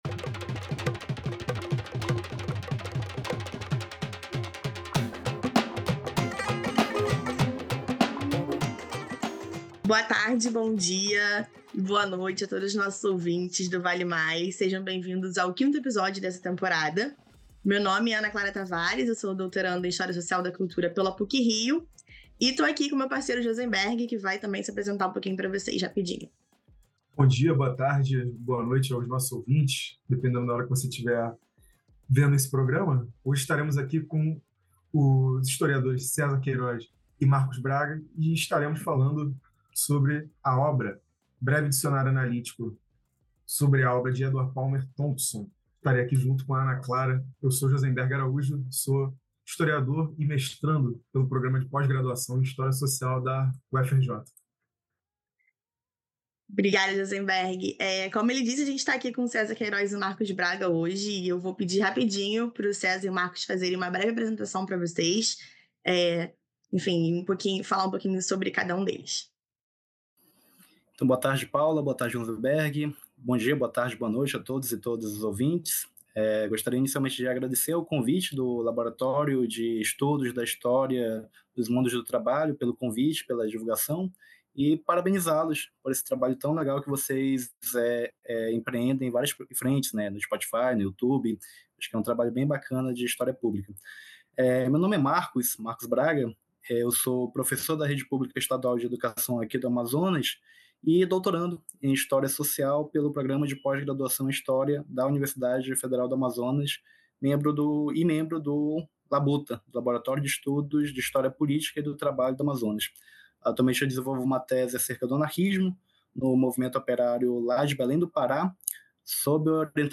Nesta temporada, convidamos pesquisadoras e pesquisadores para discutir projetos, livros e teses recentes que aprofundam debates interdisciplinares sobre os mundos do trabalho.